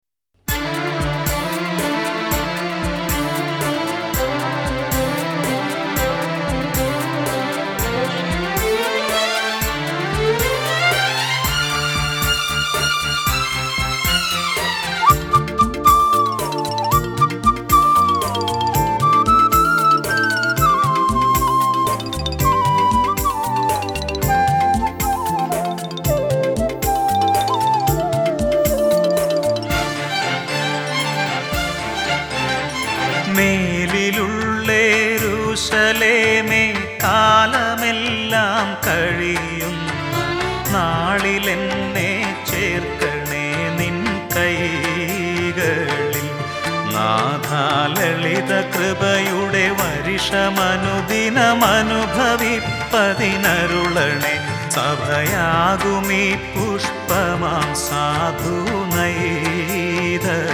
Malayalam Christian Classic Devotional Songs
(Traditional Christian Devotional Songs)
• Original Format - Audio Cassette
• Genre -Classical Christian Devotional Songs
• Melody -Classical and Semi Classical Songs